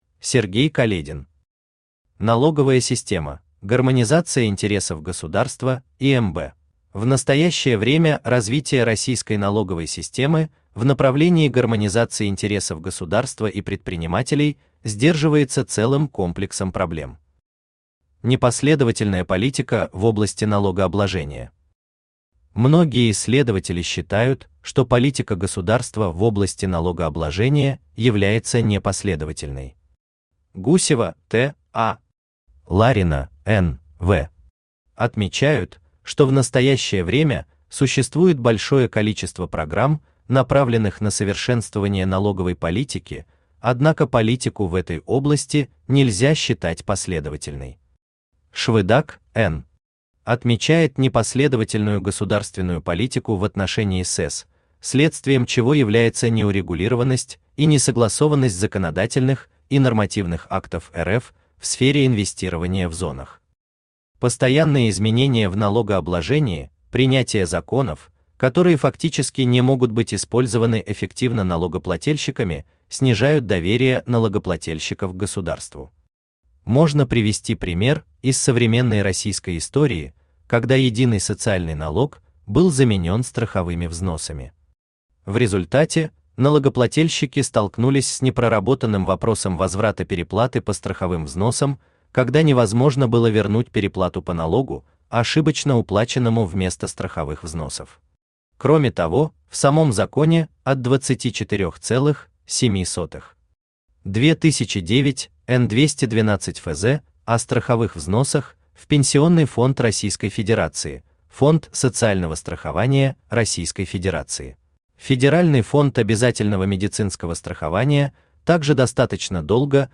Аудиокнига Налоговая система: гармонизация интересов государства и МБ | Библиотека аудиокниг
Aудиокнига Налоговая система: гармонизация интересов государства и МБ Автор Сергей Каледин Читает аудиокнигу Авточтец ЛитРес.